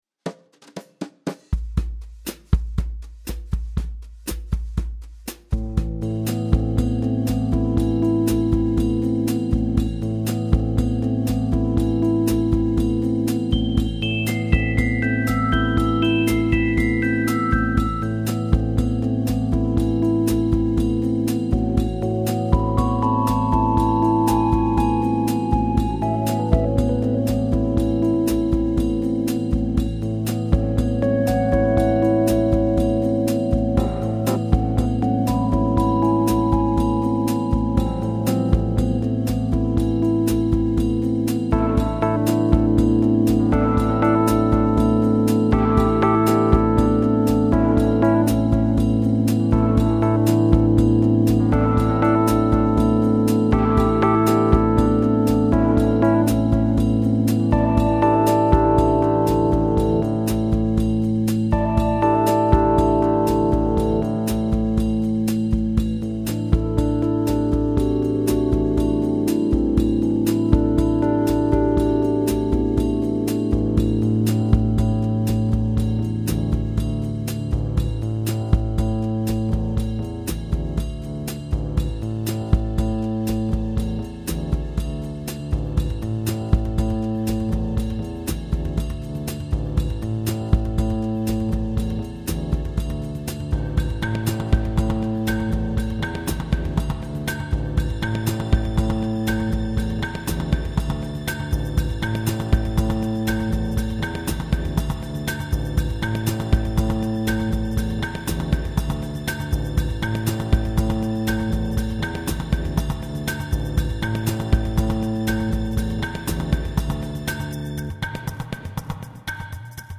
MOONPATROL - HIGH ENERGY ROCK'N'ROLL
Klampfe einstecken, Schlagzeug aussuchen und Aufnahme drücken.
Damit wäre die Stimmung der Nacht dann eingefangen und die Wache wurde zum 6-Stunden-Jam mit den Maschinen.